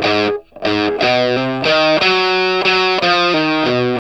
WALK1 60 GS.wav